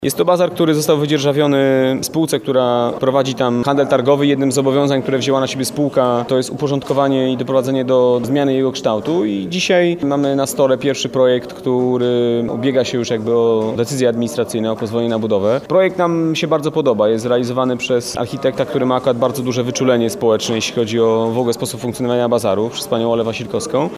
– Warunkiem wydzierżawienia terenu była obietnica zmian – mówi wiceprezydent Warszawy Michał Olszewski.